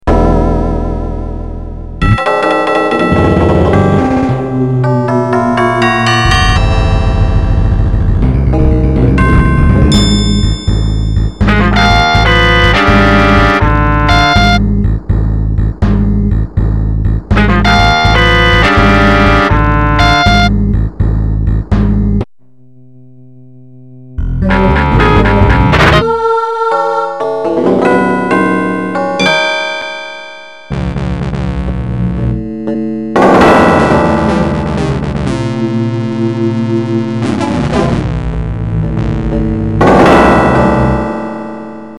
Absurdist Composition